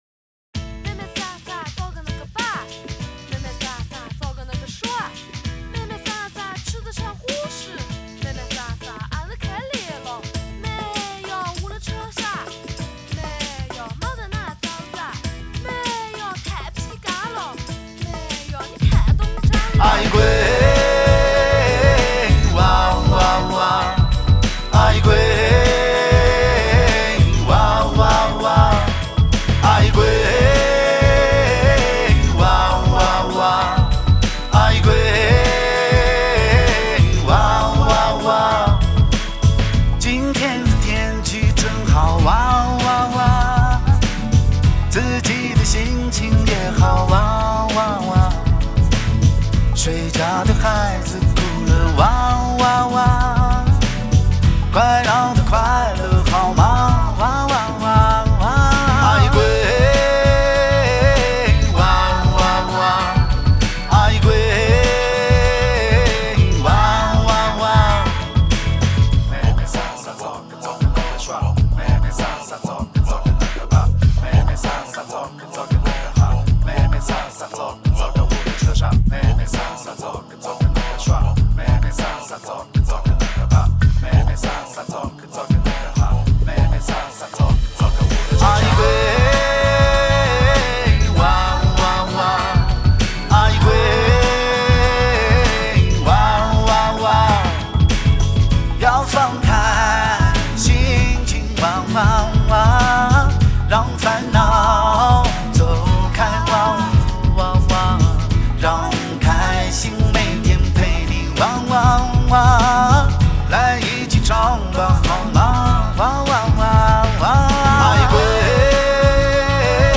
童声的清澈和现代音乐元素的结合给人耳目一新的感觉。